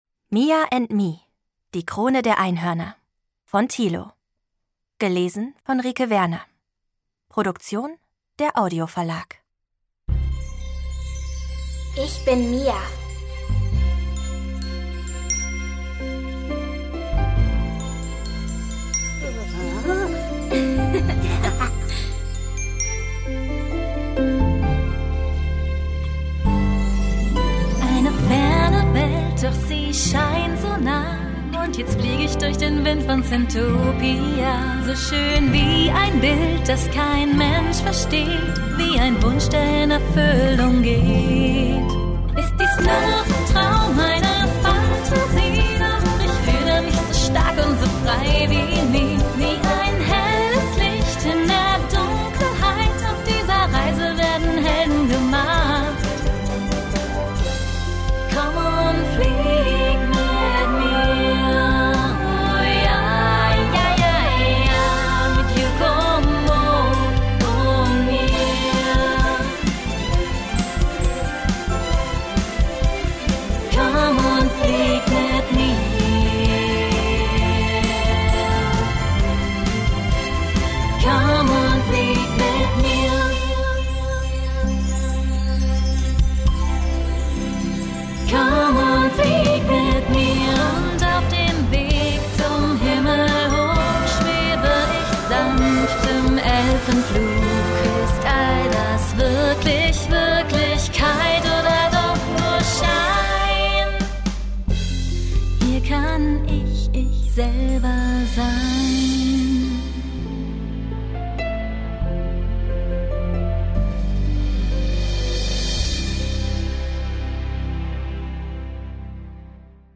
Mia and me: Die Krone der Einhörner – Das Hörbuch zur 2. Staffel Lesung mit Musik